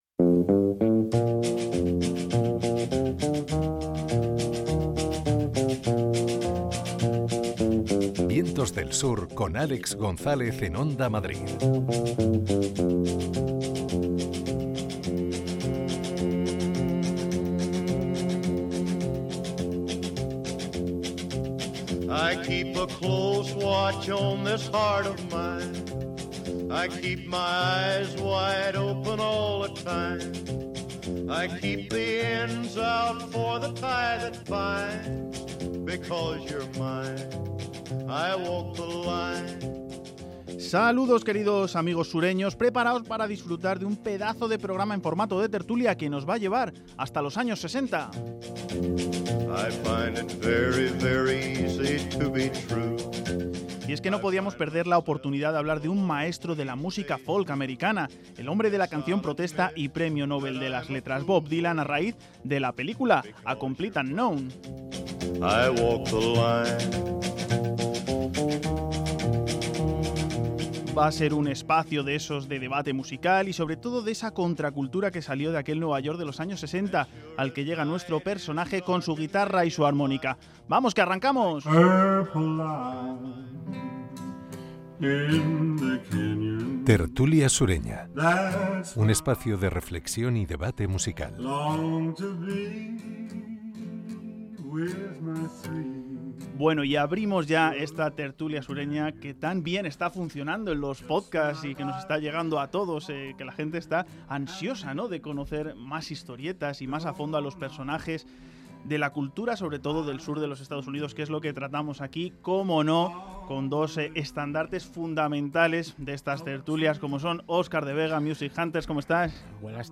VDS 5X22: Tertulia sureña, los inicios de Bob Dylan